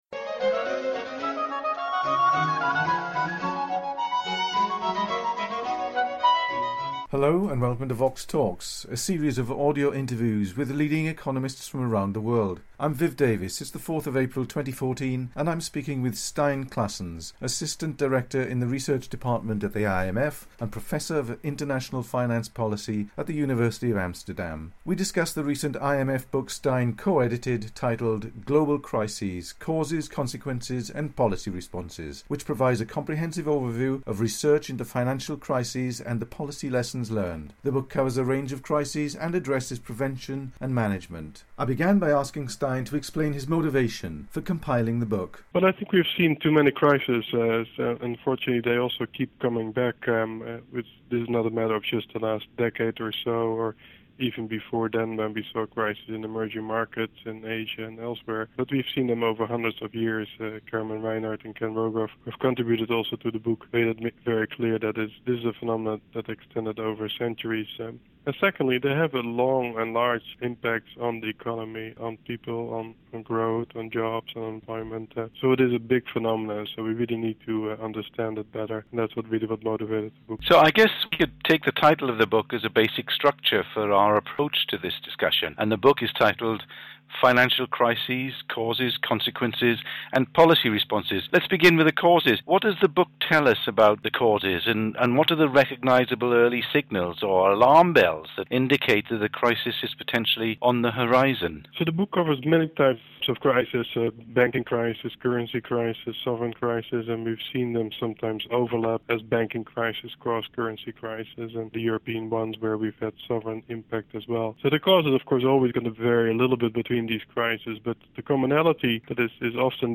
The book provides a comprehensive overview of current research into financial crises and the policy lessons learned. They discuss crisis prevention and management, and the crisis in the Eurozone. The interview was recorded in April 2014.